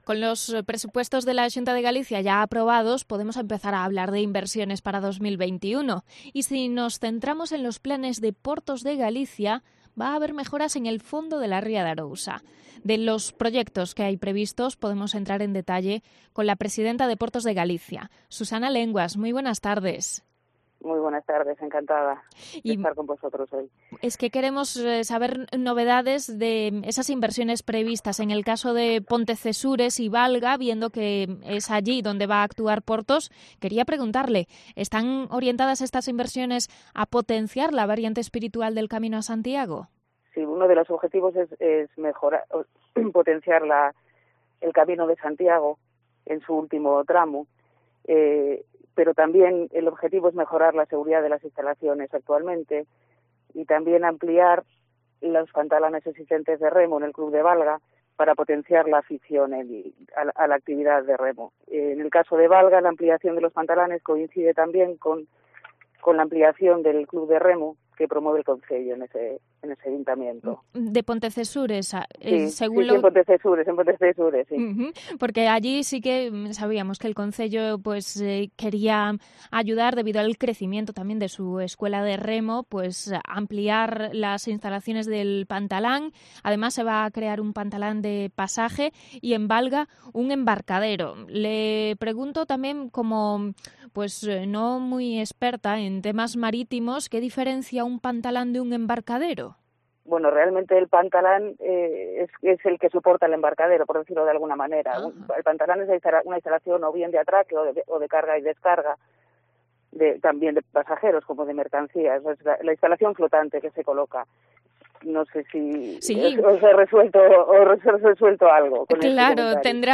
Entrevista a la presidenta de Portos de Galicia, Susana Lenguas, sobre inversiones en la ría de Arousa